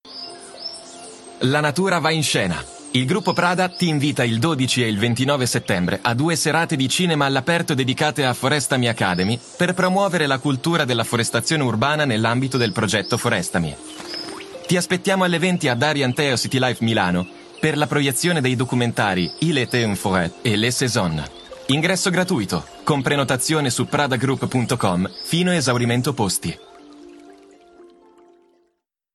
Male
Confident, Engaging, Friendly, Natural, Versatile, Corporate, Deep, Young
Microphone: SE Electronics 2200A / Shure MV7